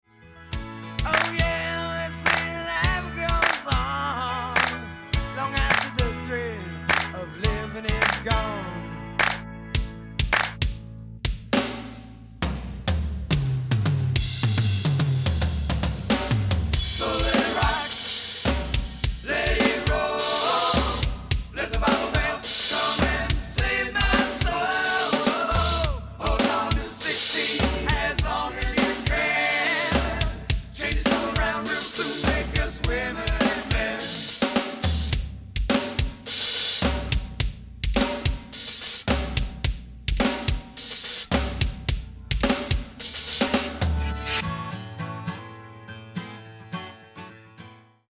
Iconic drum fill & breakdown.